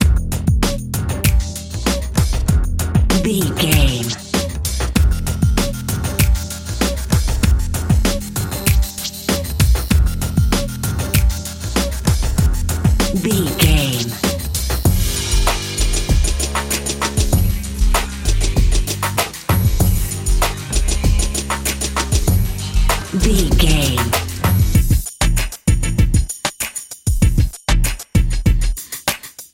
Aeolian/Minor
WHAT’S THE TEMPO OF THE CLIP?
synthesiser
drum machine
hip hop
soul
Funk
neo soul
acid jazz
energetic
bouncy
funky